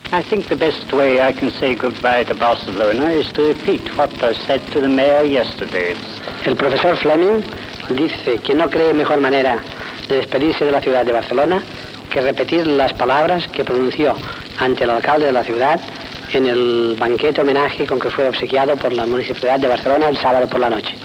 Paraules de comiat i agraïment a la ciutat de Barcelona del doctor Alexander Fleming